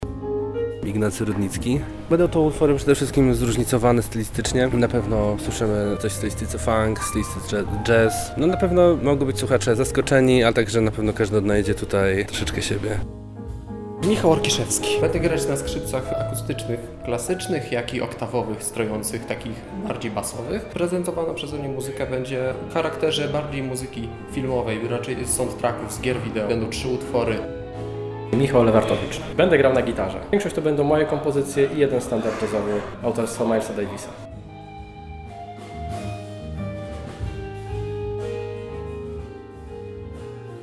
O tym mówili tuż przed wejściem na scenę: